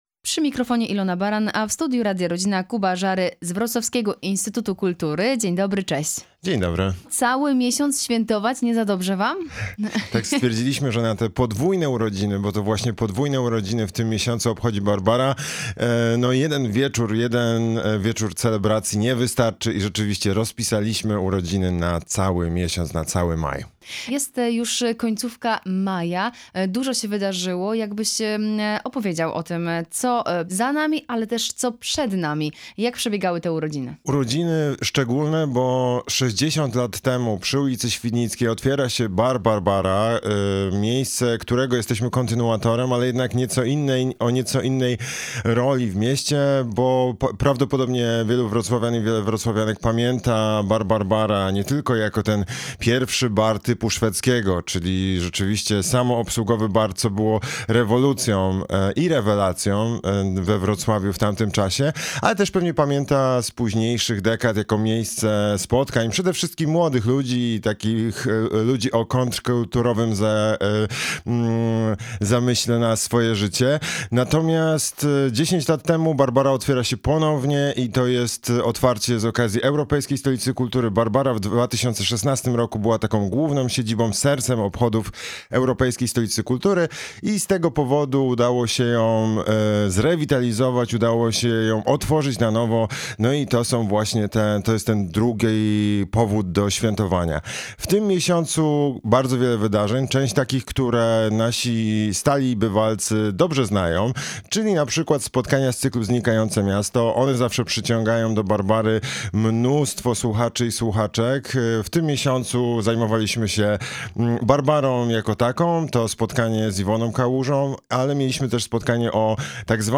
CAŁA ROZMOWA: